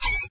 animal_rat_random.12.ogg